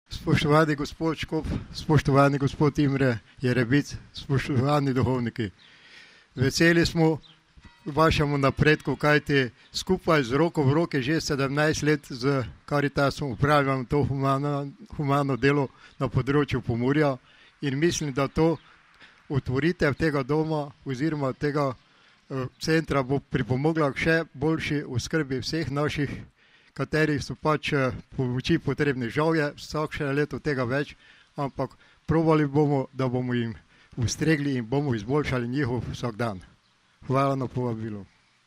11.00 blagoslovitev Lazarjevega doma
Audio pozdrava